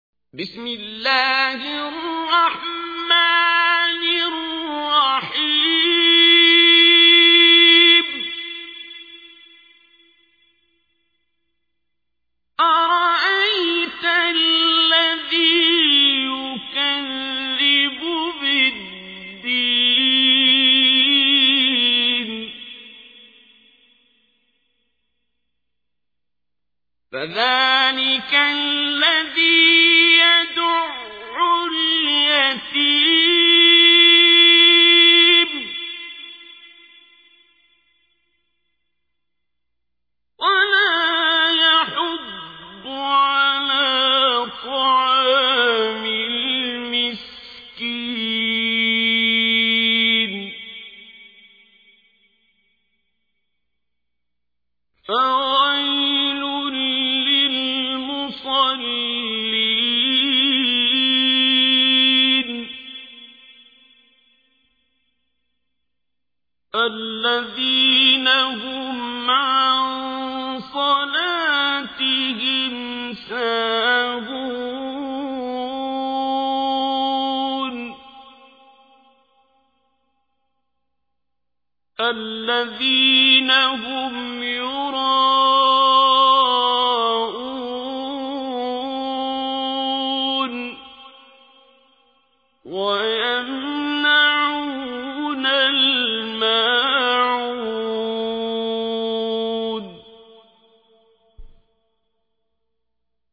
تحميل : 107. سورة الماعون / القارئ عبد الباسط عبد الصمد / القرآن الكريم / موقع يا حسين